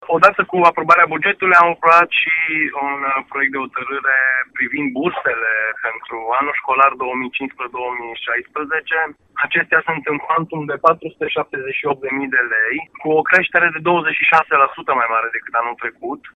Viceprimarul municipiului Sighișoara, Eugen Bândea: